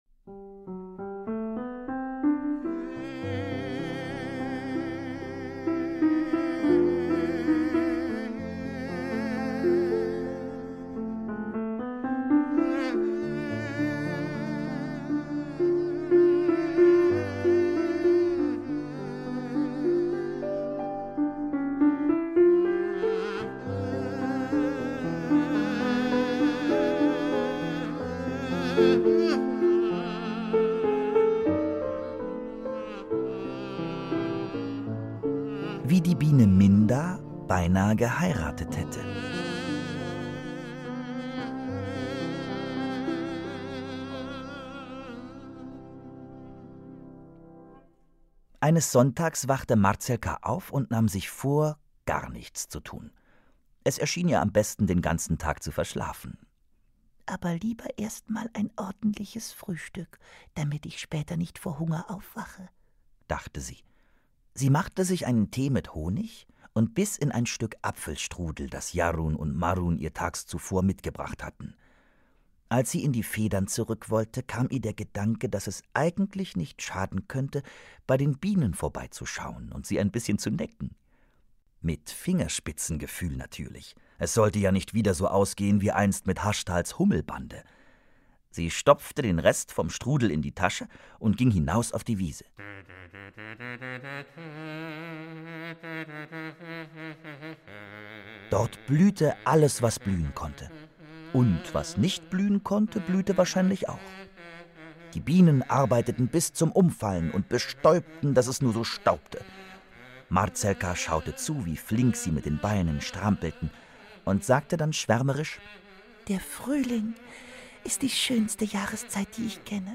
Hörbuch 4